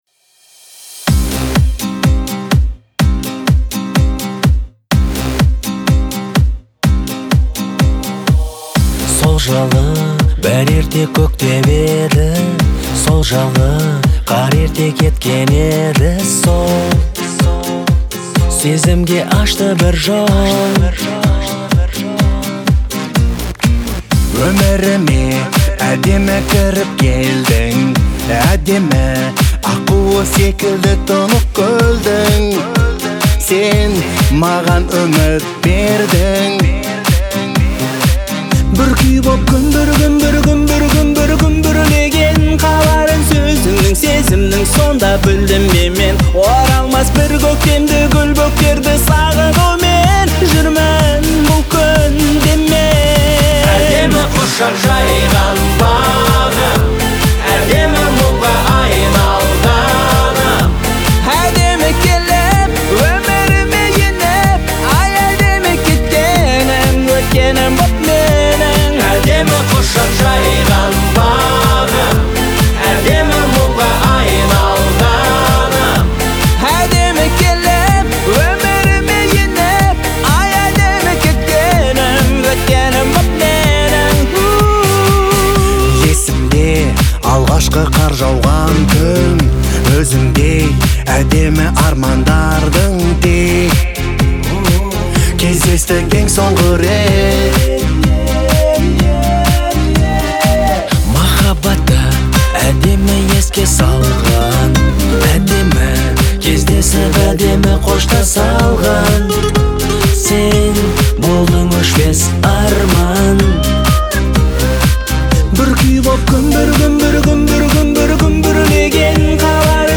это яркий пример казахского поп-рока